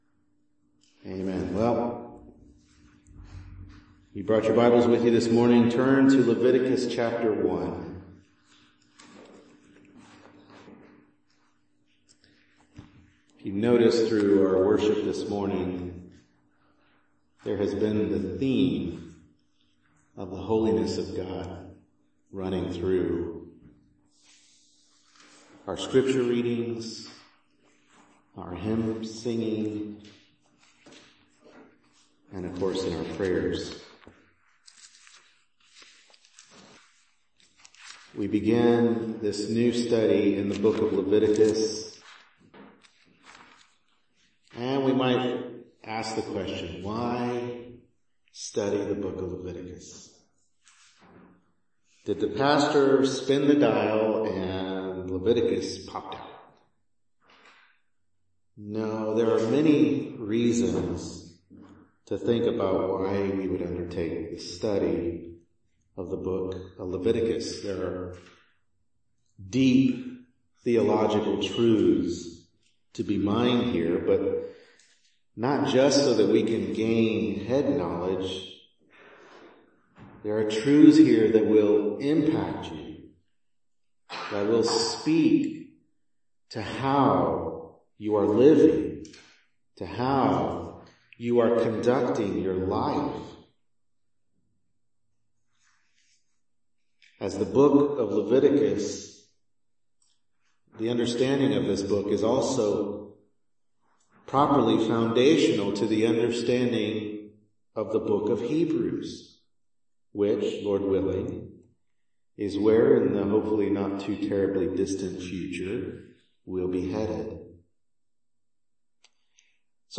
Leviticus 1:1-2 Service Type: Morning Worship Service Bible Text